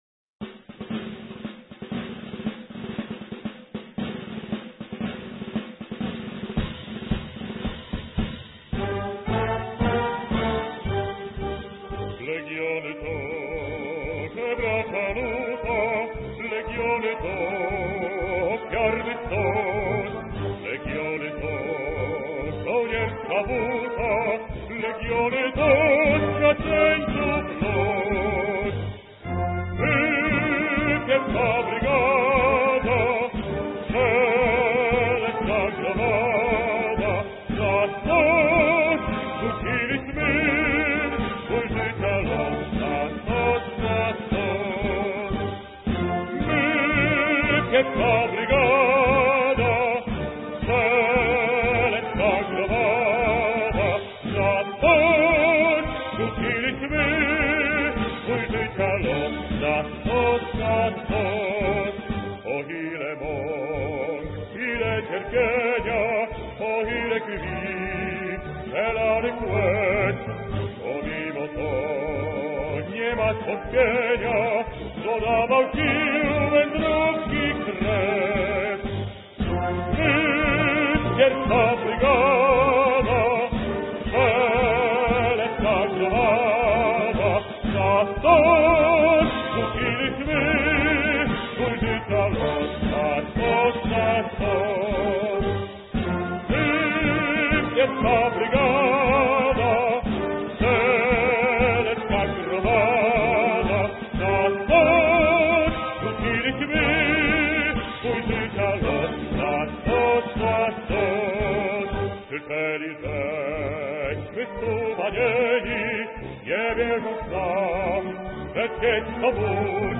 Вы не забыли полный достоинства и благородства «Марш Первой бригады»? Послушайте его и в таком ещё мужественном исполнении (скачать):